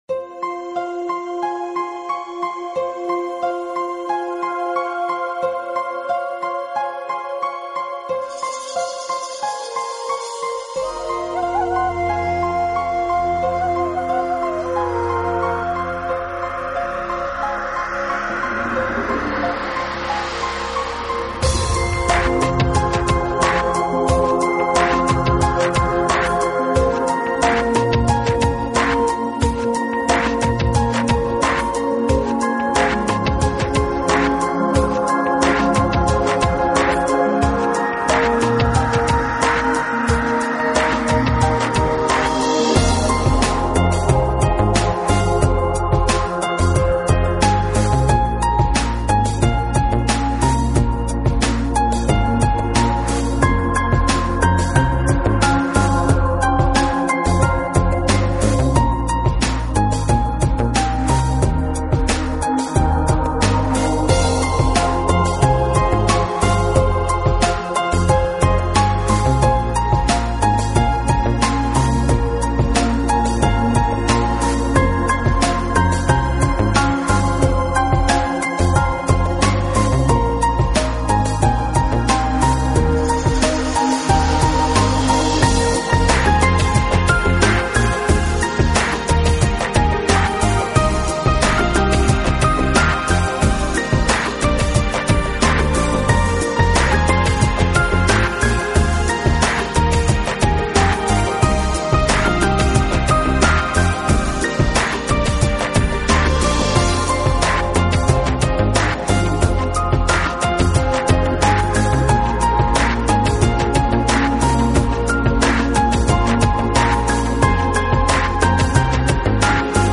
Genre: New Age / World
感觉。就像一个神奇的旅程，使您感受到独特的氛围，柔软和轻盈、微妙和华晨着